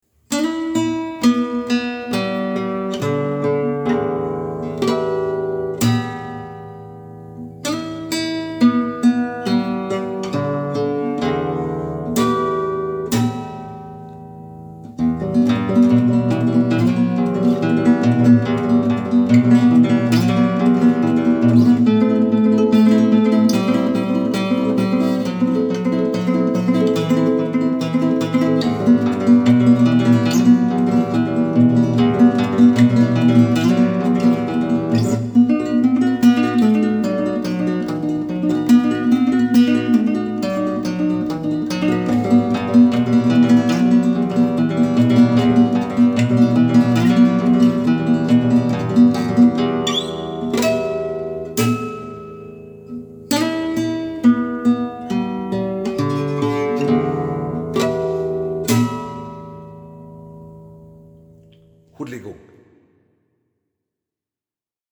Gitar
Endelig en låt med BARE gitar!
Det kuleste med denne er at den er tatt opp med min nye Samsung, liggende på stuebordet.
Ett take, rett inn. Mastret fila og la på litt klang!